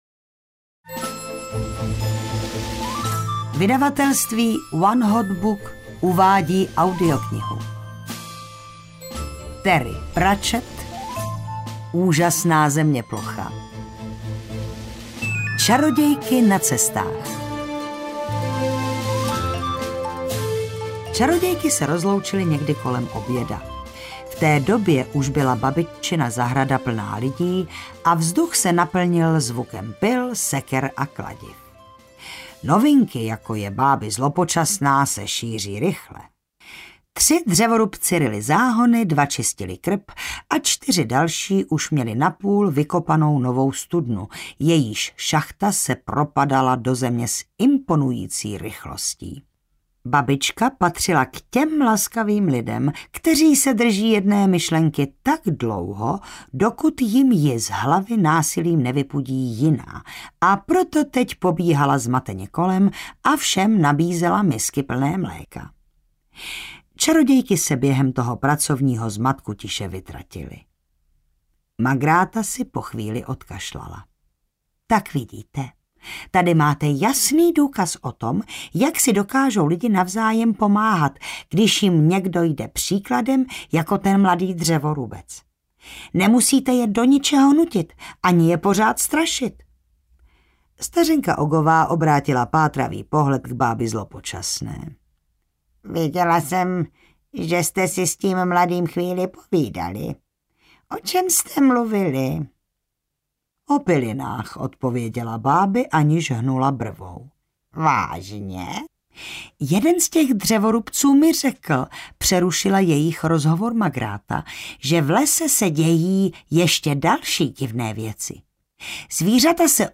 Čarodějky na cestách audiokniha
Ukázka z knihy